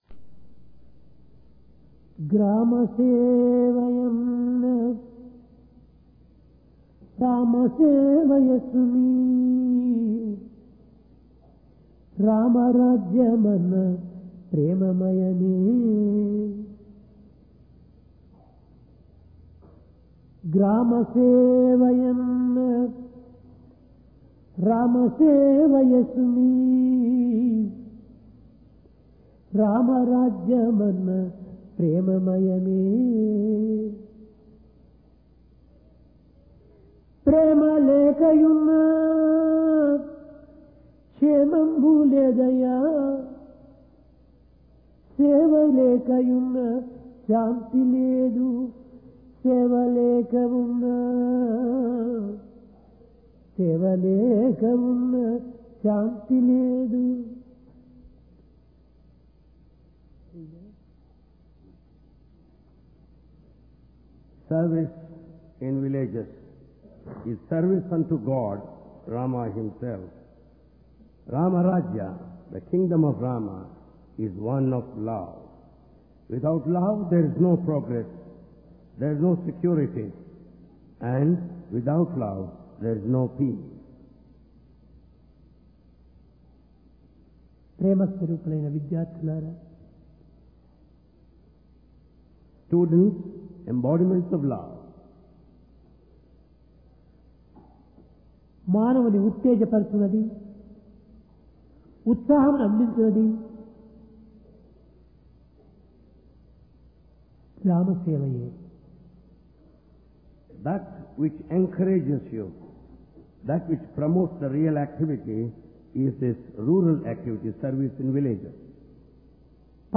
Divine Discourse on Service | Sri Sathya Sai Speaks
Discourse